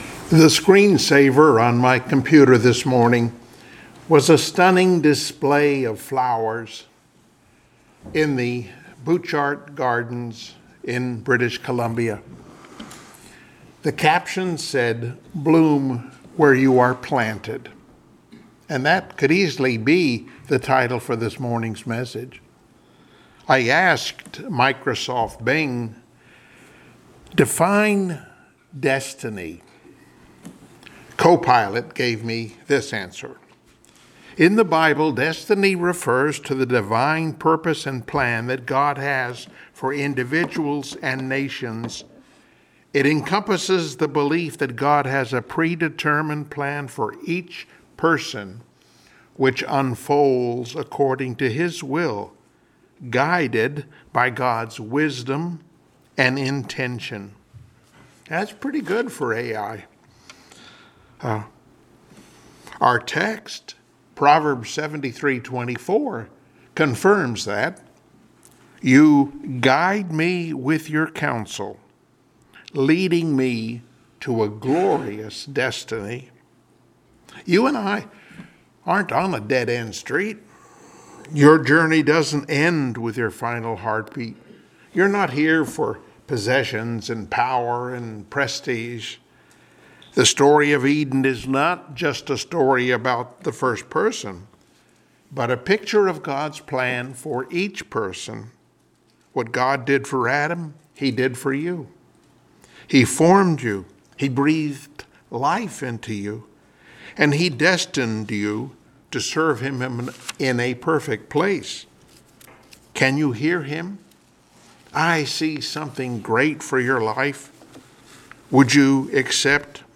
Service Type: Sunday Morning Worship Topics: Bodily Ressurection , Gifts and Callings of God , Glorious Destiny